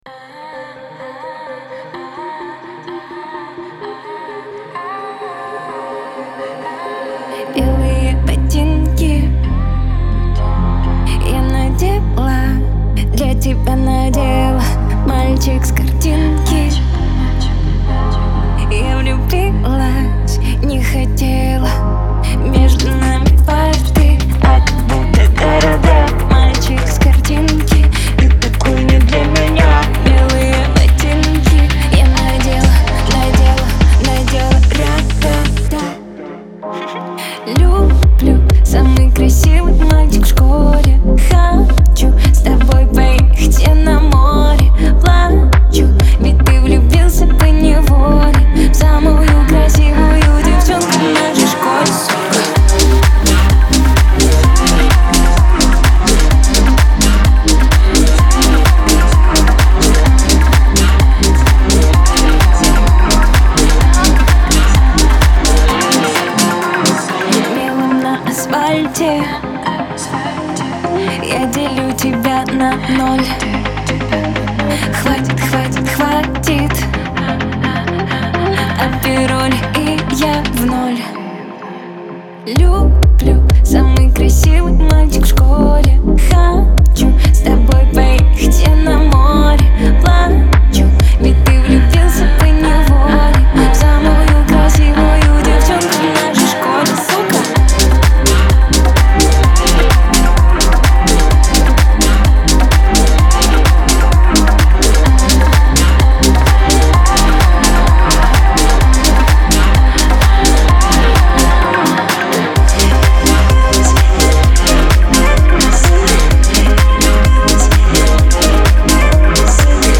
трогательная и мелодичная песня